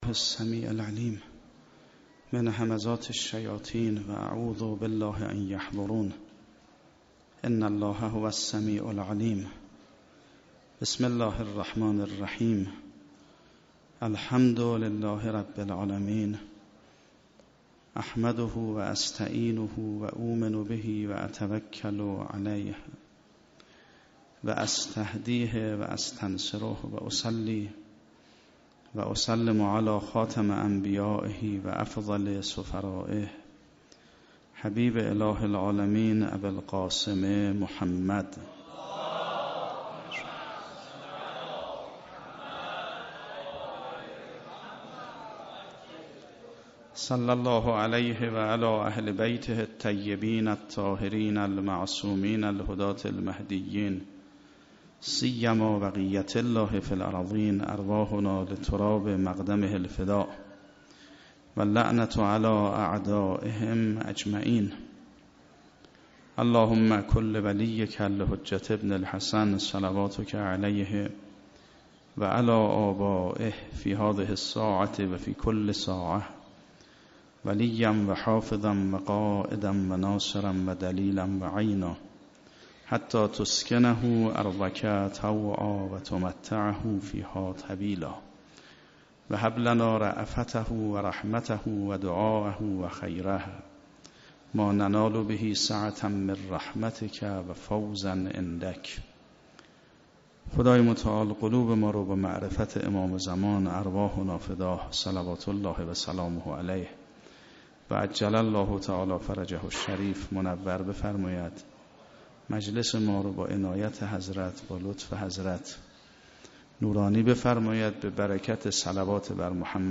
شب هشتم رمضان 96 - حسینیه حق شناس - سخنرانی